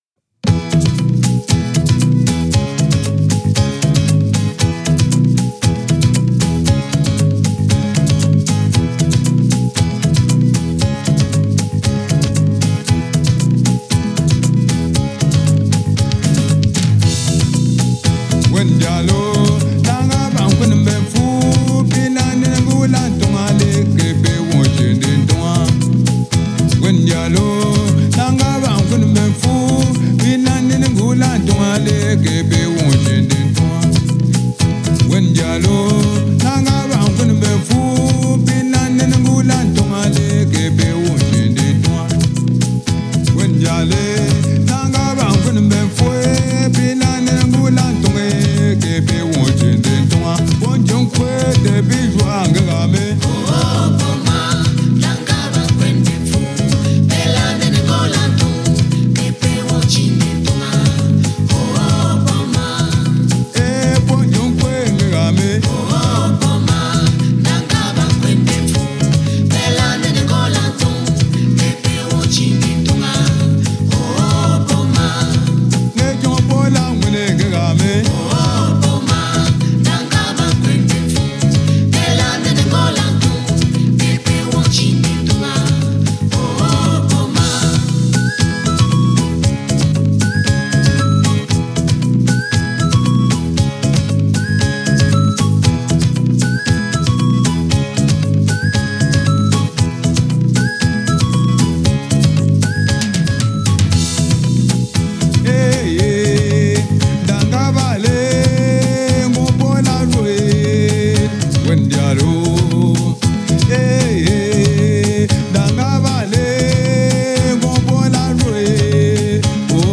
Genre: Africa, World